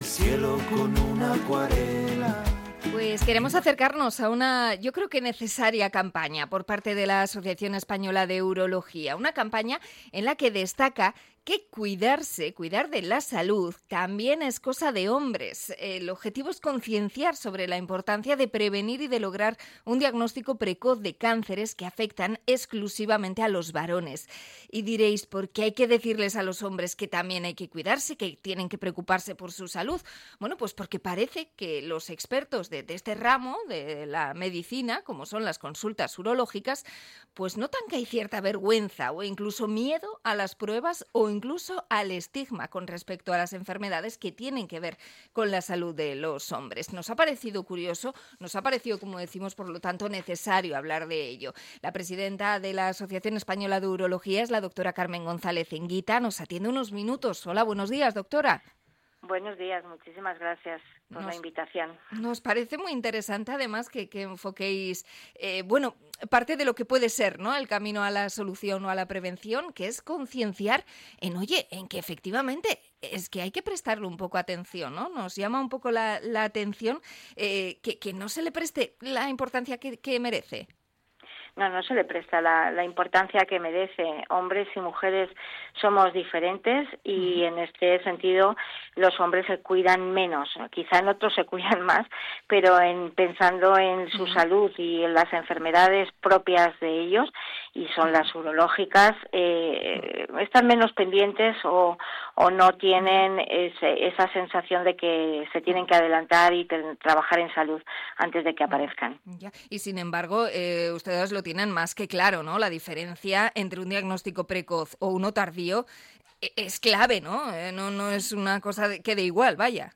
Entrevista con la Asociación Española de Urología
INT.-UROLOGOS.mp3